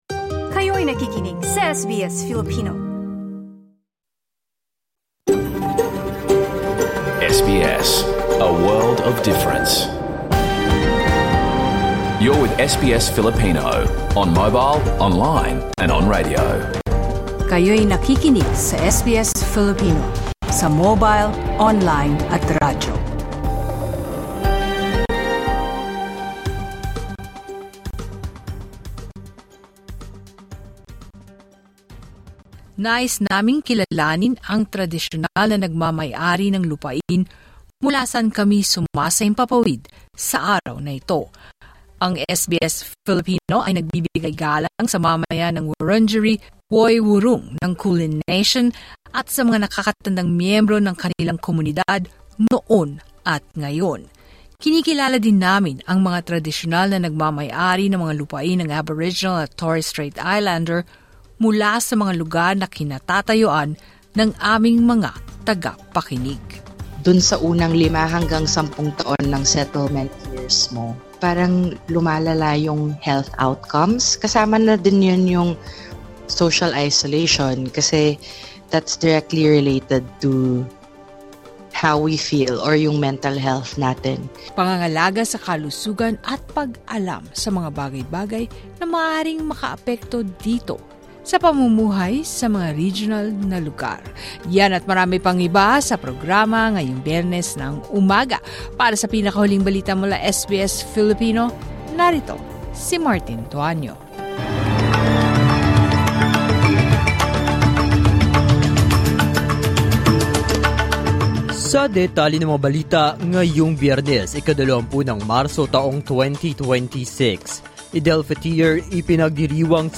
Key Points SBS brings diverse communities together at Bondi Pavilion for Harmony Week multilingual broadcast, including the Filipino radio program. Stay tuned for the latest updates from the Philippines: a significant hike in petrol prices, the ongoing repatriation of Filipinos from the Middle East, and other breaking news.